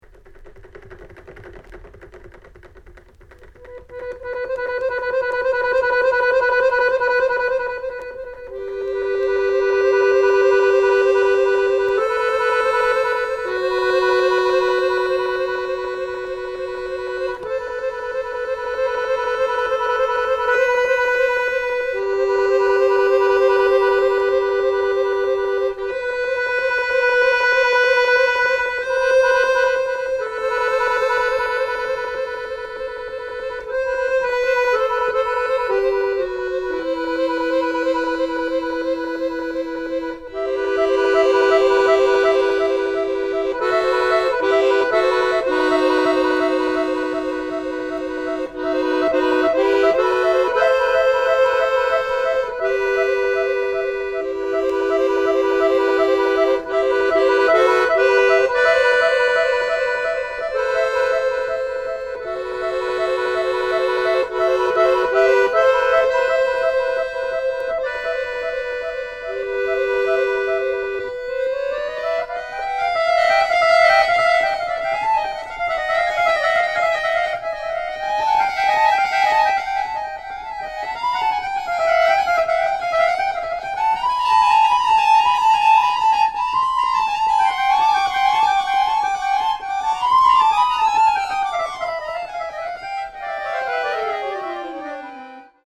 キーワード：ドローン　即興　フォーク